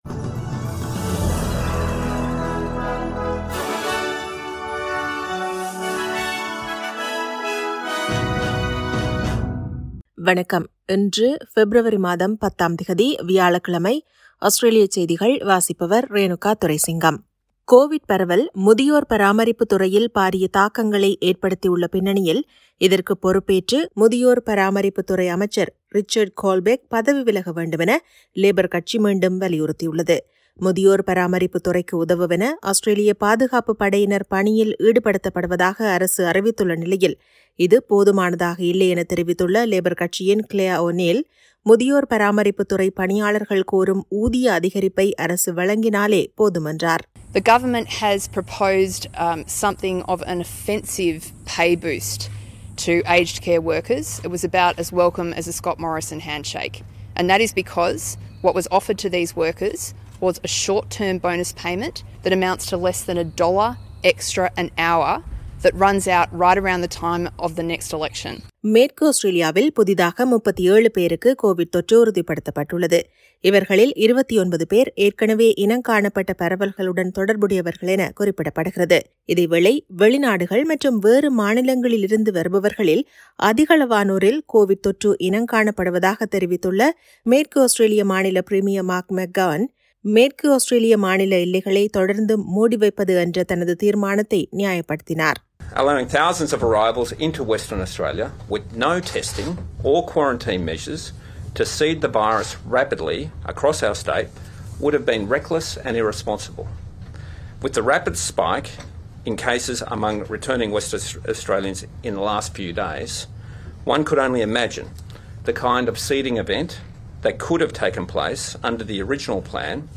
Australian news bulletin for Thursday 10 February 2022.